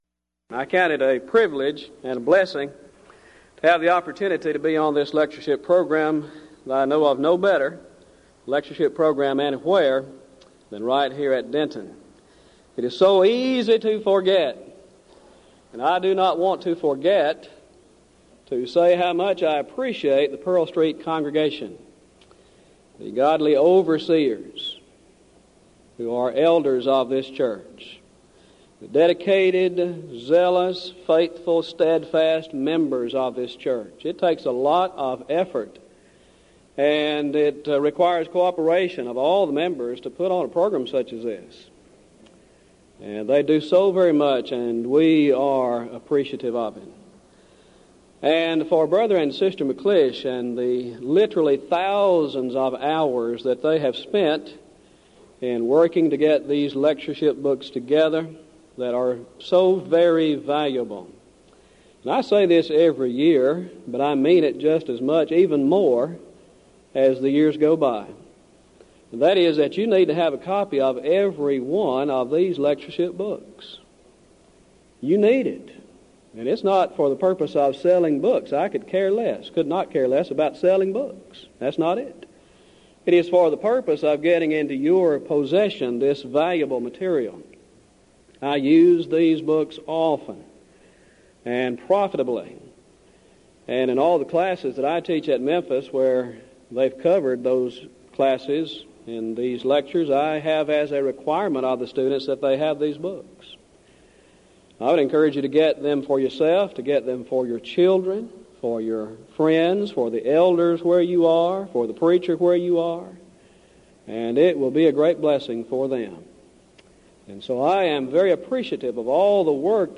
Event: 1998 Denton Lectures Theme/Title: Studies in the Books of I, II Peter and Jude